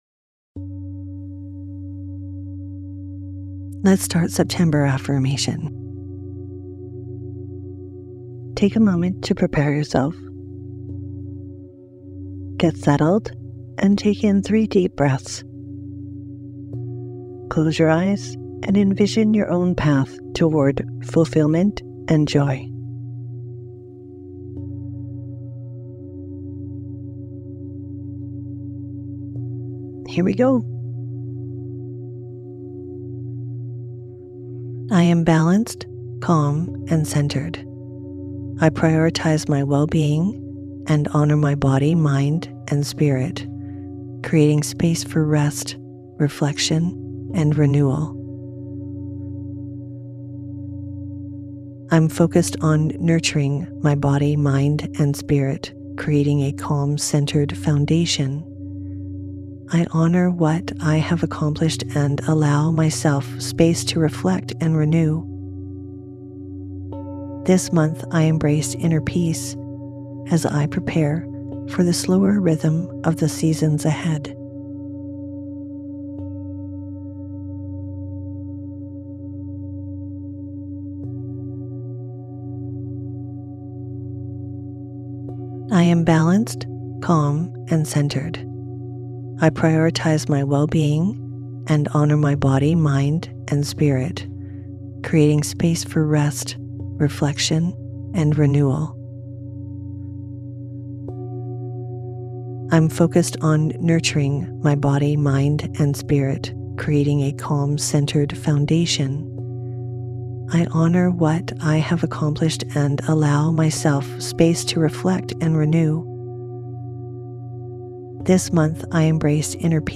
Sleep versions feature the affirmation repeated three times, creating repetition for deeper impact and greater benefits.